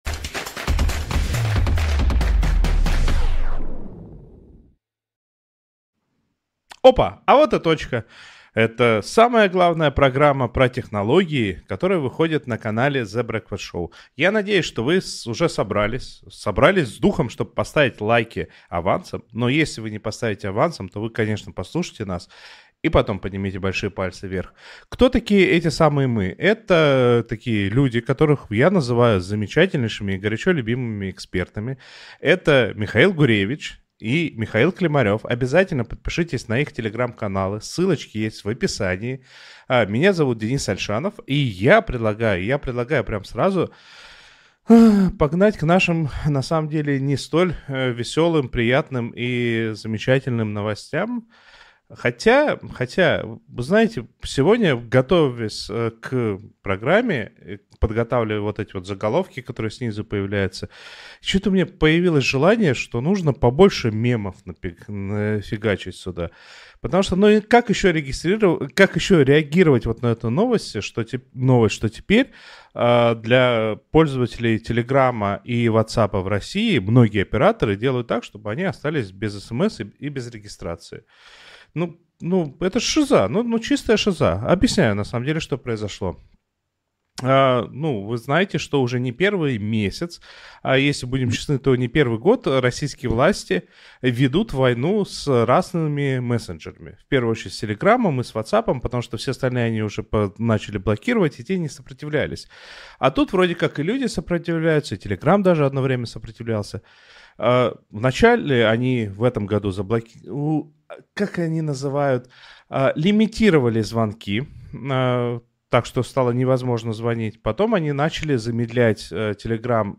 говорит с экспертами про интернет и технологии в нашей жизни